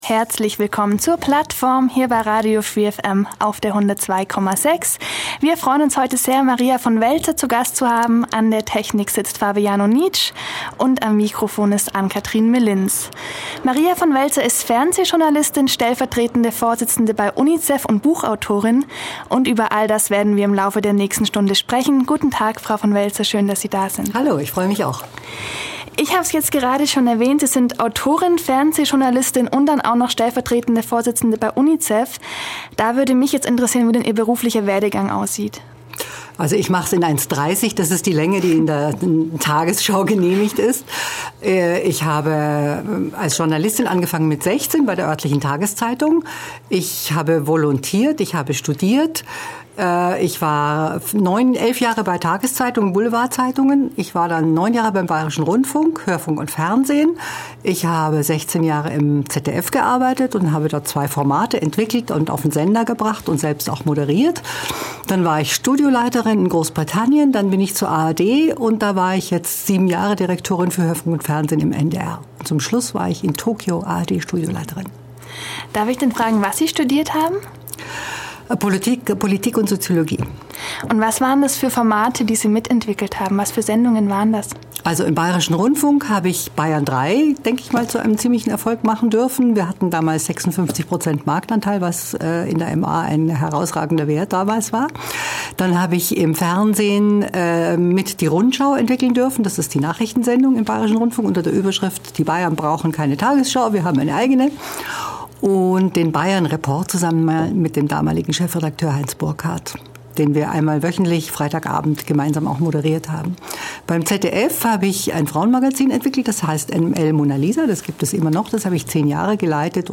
Heute zu Gast in der Plattform ist die Fernsehjournalistin Maria von Welser. Sie leitete und moderierte unter anderem fast zehn Jahre lang das ZDF-Frauenjournal „ML Mona Lisa“.